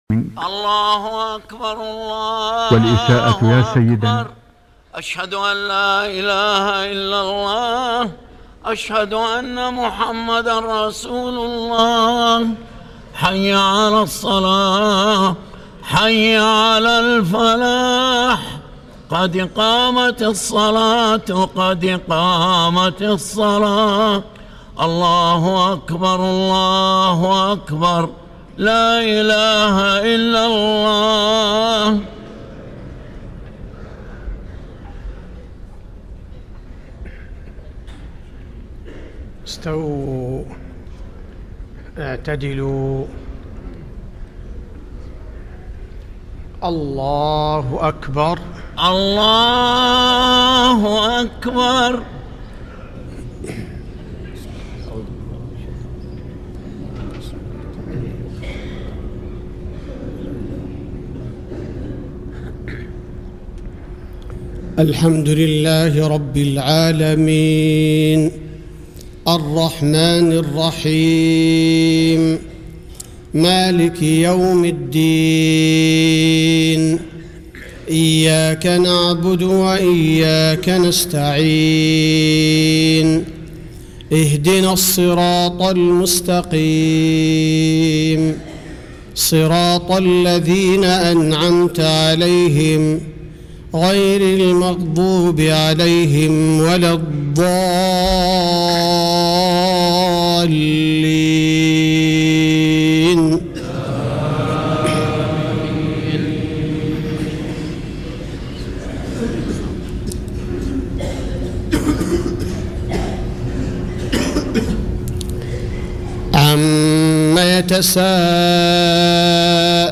صلاة الفجر ١٢ محرم ١٤٣٤هـ سورة النبأ | > 1434 🕌 > الفروض - تلاوات الحرمين